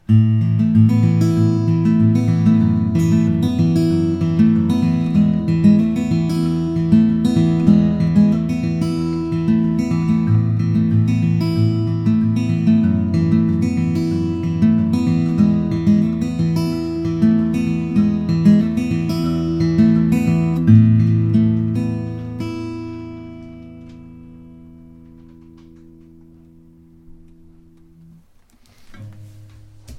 He made under fixed conditions samples of each guitar.
Picking 3